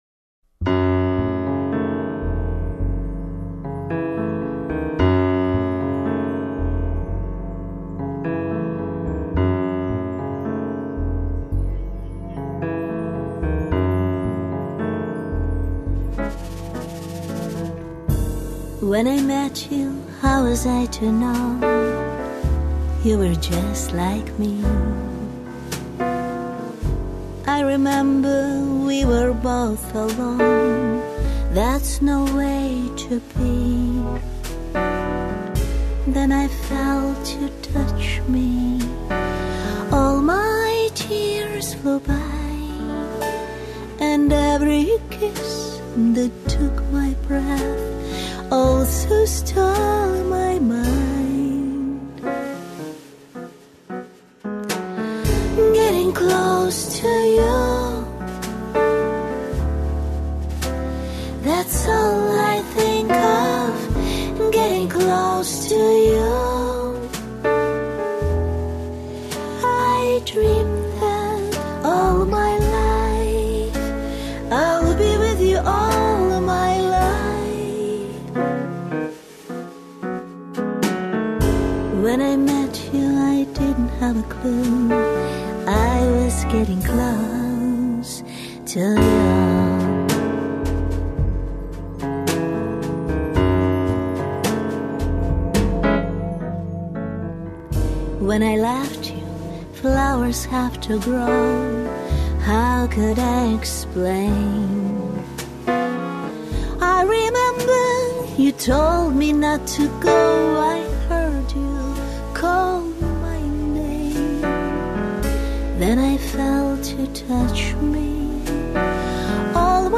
singing the title song